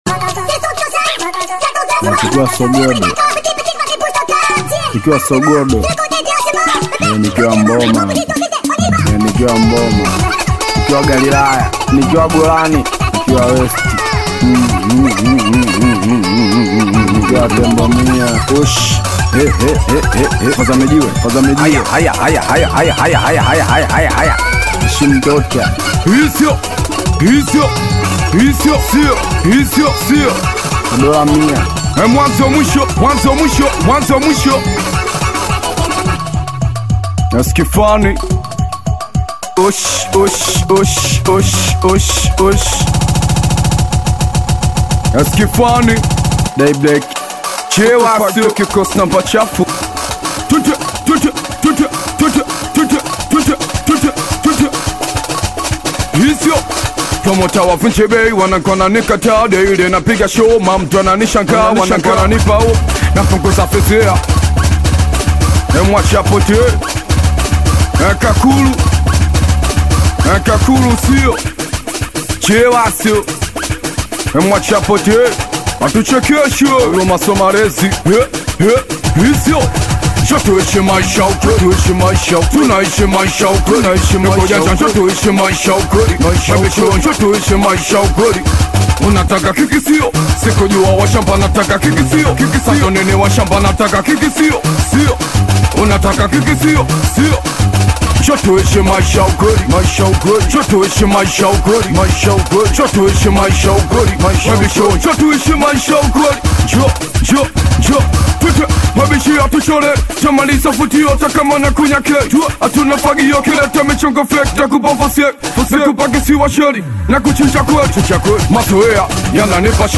SINGELI MUSIC